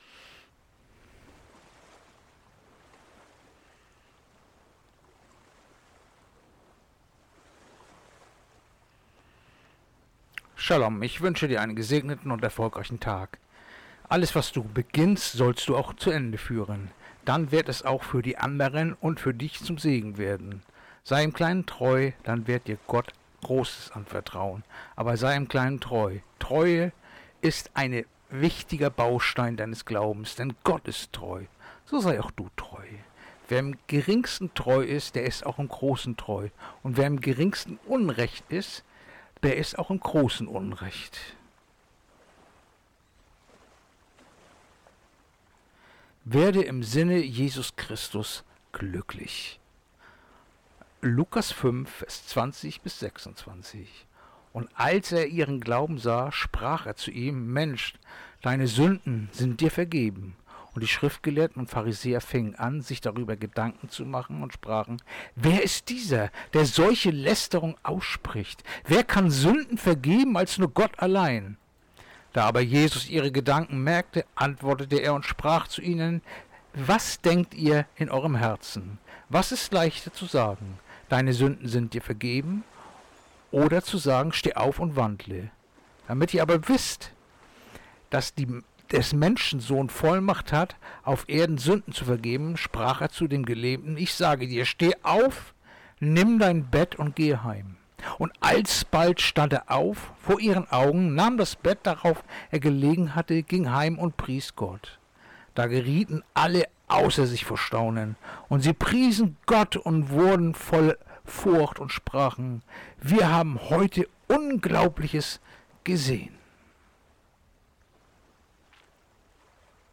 Andacht-vom-29.-Januar-Lukas-5-20-26